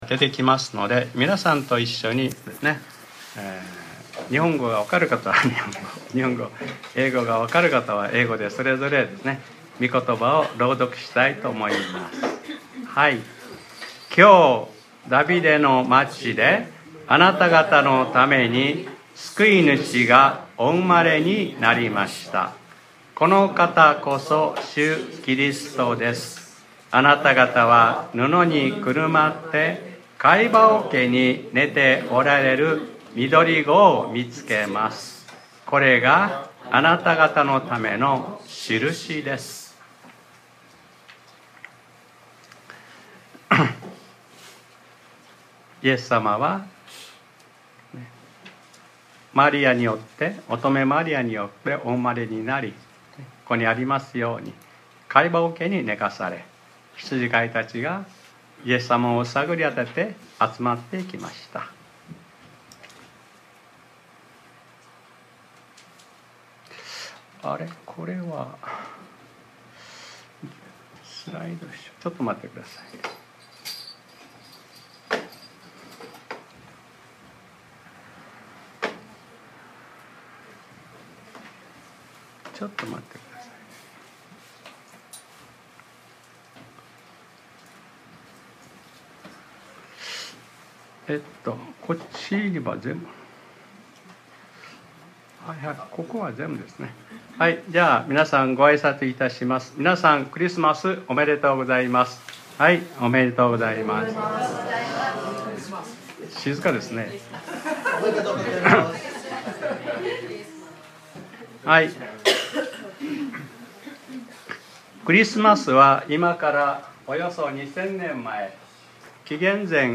2024年12月22日（日）礼拝説教『 最も価値あるもの 』